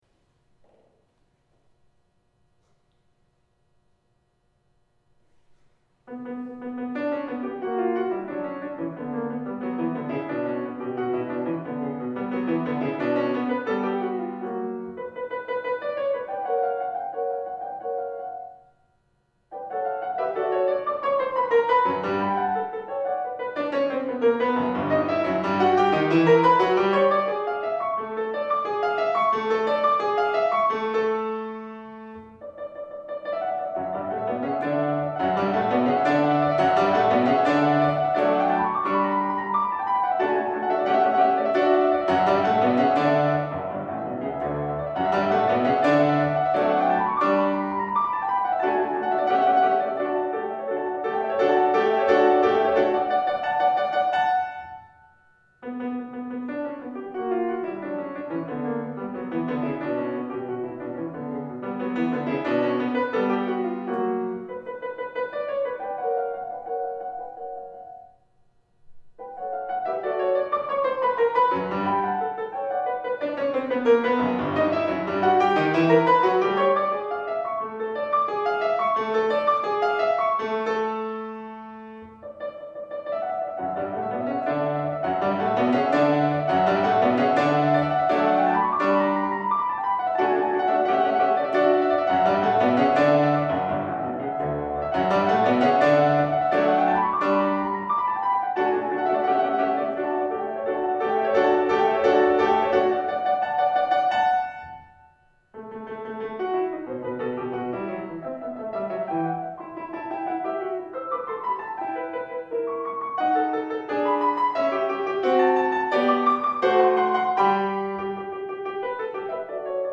A sampling of my solo and chamber music recordings: